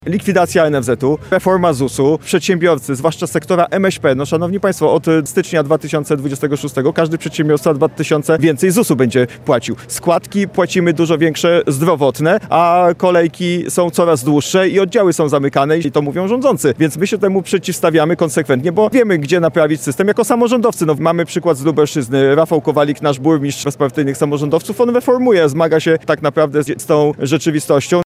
W Lublinie odbyło się spotkanie działaczy i sympatyków Bezpartyjnych Samorządowców. Okazją do tego była dyskusja informacyjno-programowa w sprawie przyszłych wyborów do Sejmu i Senatu RP.
Spotkanie zorganizowano w hotelu Victoria przy ul. Narutowicza w Lublinie.